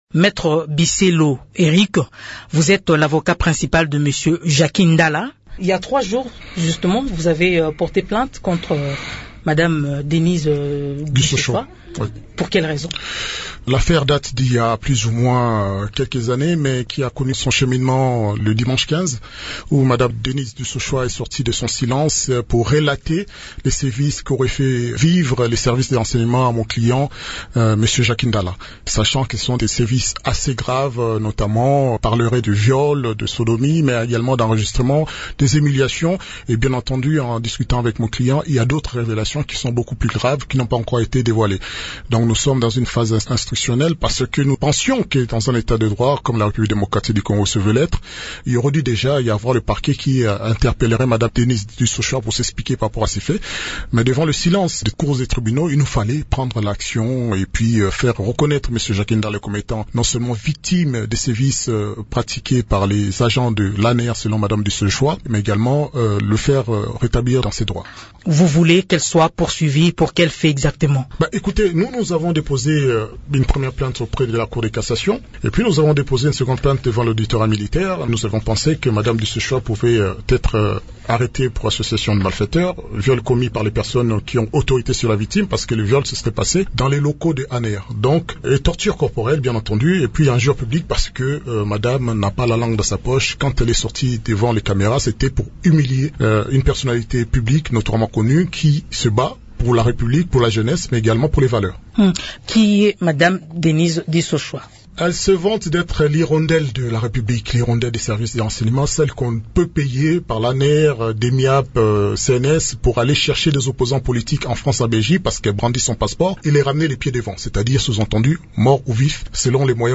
Dans une interview à Radio Okapi